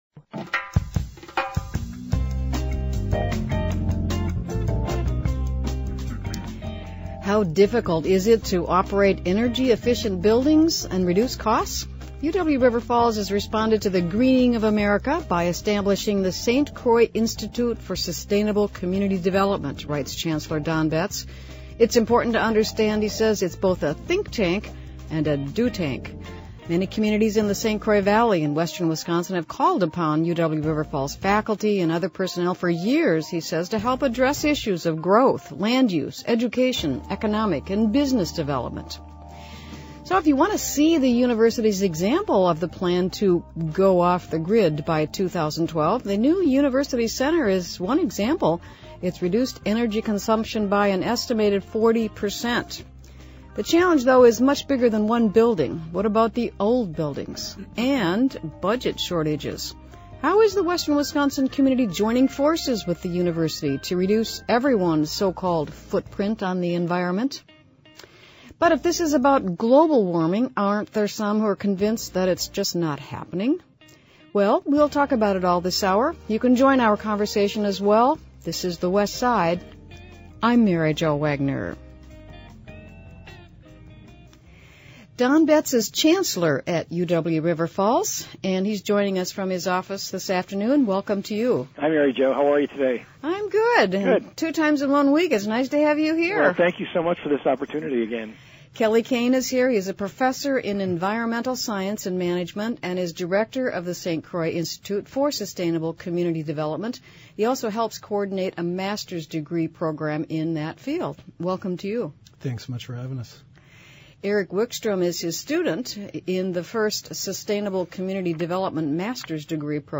Download WPRInterview.mp3